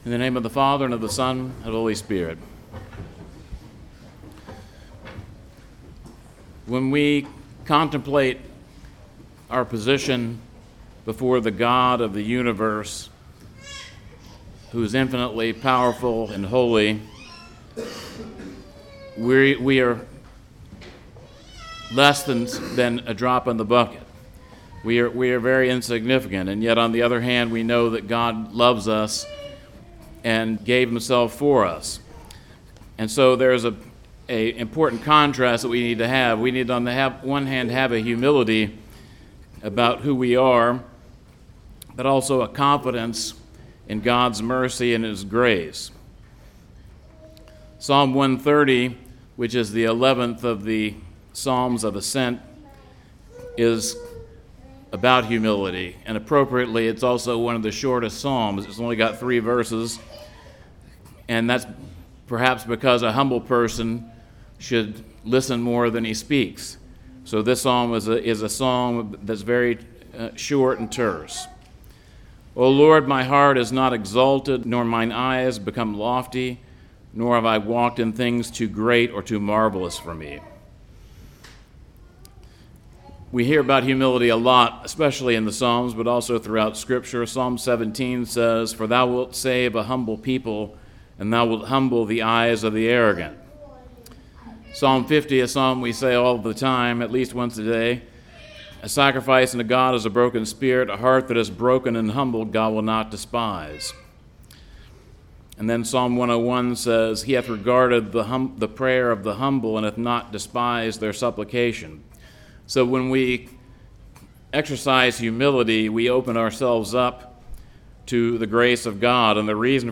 2024 The Way of Humility (Psalm 130) Preacher